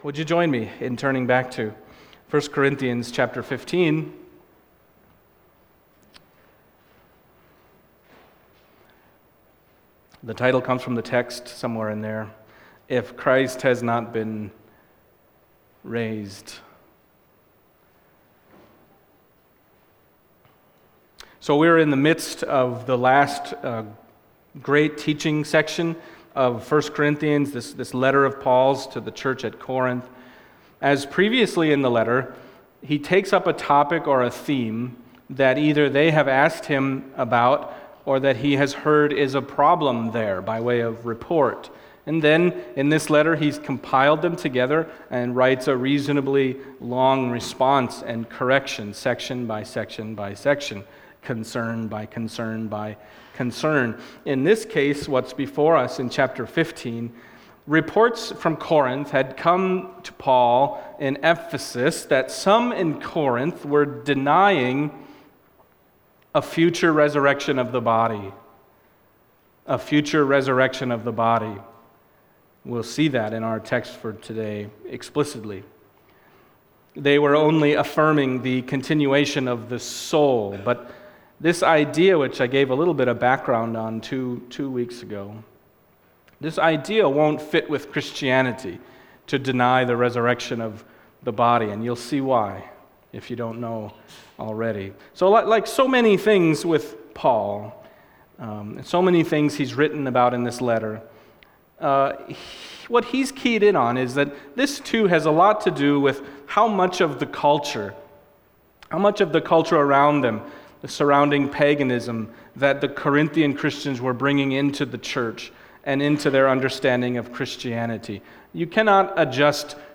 1 Corinthians 15:12-20 Service Type: Sunday Morning 1 Corinthians 15:12-20 « The Astonishing Resurrection of Jesus Christ the Firstfruits…